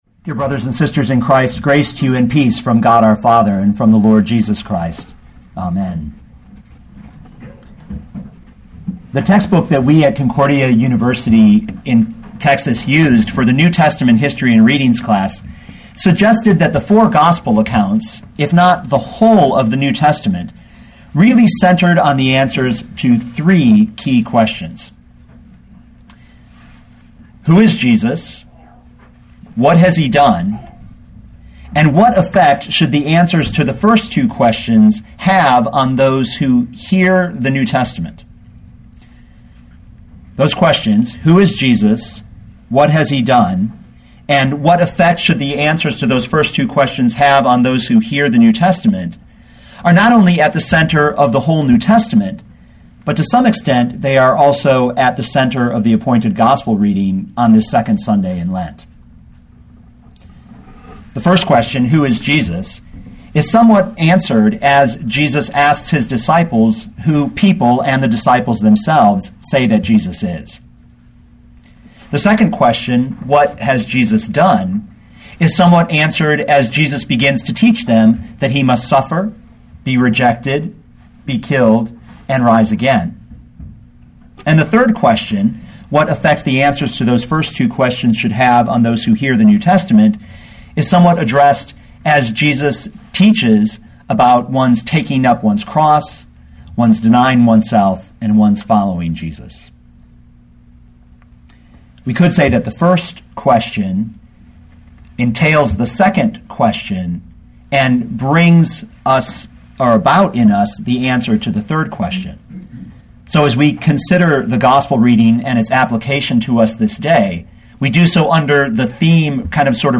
2012 Mark 8:27-38 Listen to the sermon with the player below, or, download the audio.